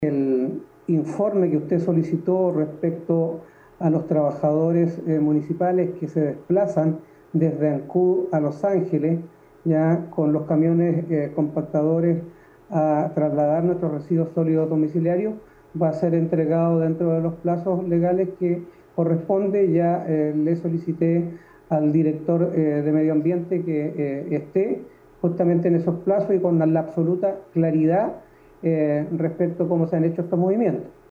Al respecto, el alcalde Carlos Gómez señaló que respecto de la solicitud de un informe acerca de las consecuencias laborales que implica para los funcionarios trasladar la basura de Ancud a Los Ángeles, señaló que dentro del plazo legal se entregará ese reporte.
13-ALCALDE-RESPUESTA.mp3